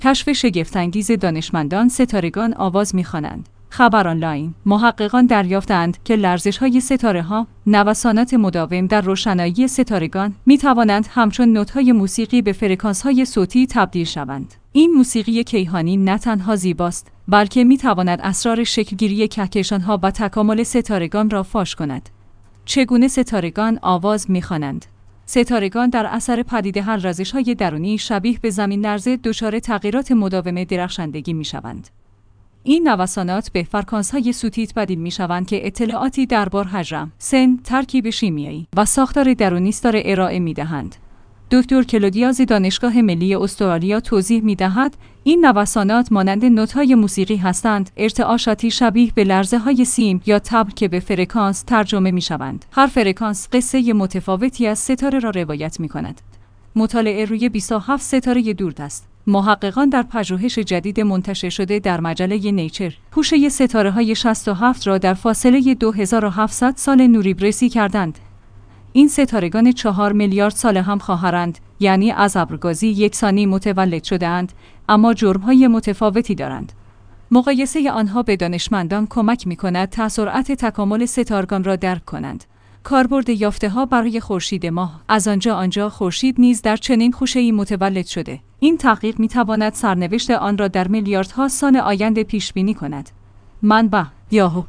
خبرآنلاین/ محققان دریافته‌اند که لرزش‌های ستاره‌ها، نوسانات مداوم در روشنایی ستارگان، می‌توانند همچون نت‌های موسیقی به فرکانس‌های صوتی تبدیل شوند.